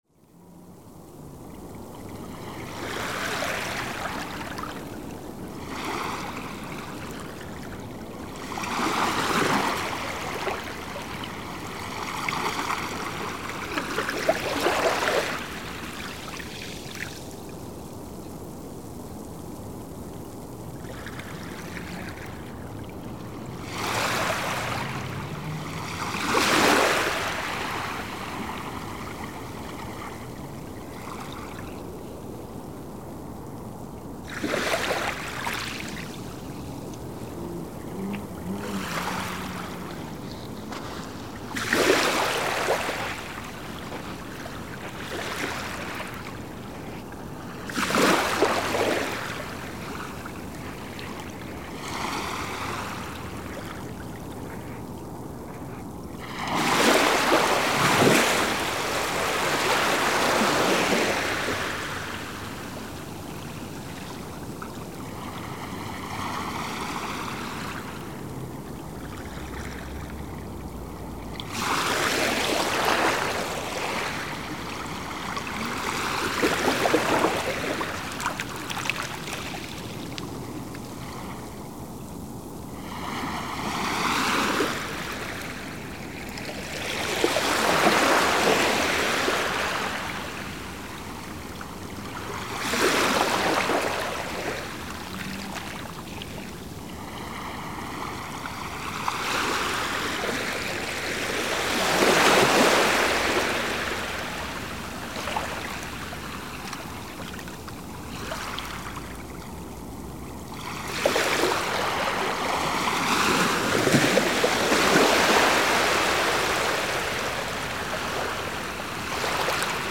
Skrifað í Náttúra | Merkt Öldur, Bílaumferð, Fjara, Korg MR1000, Sea, Seashore, Sennheiser ME62 | 4 athugasemdir
Óvenju hljótt er því á þessum stað miðað við ýmsa staði í nágrenni Reykjavíkur. Þó má greina þungan nið bílaumferðarinnar sem hávært grunnsuð.
Við upptöku þessarar hljóðritunar var nauðsynlegt að nota lágtíðnisíur til þess að draga niður í lægstu tíðninni frá umferðinni sem fáir heyra en hefur truflandi áhrif á upptökur.
Hljóðnemum var stillt upp í flæðarmálinu og má heyra að hægt og rólega fjarar undan þeim. Tekið var upp í DSD 1 Bit/5,644 MHz, með Sennheiser ME62 hljóðnemum í 90° horni.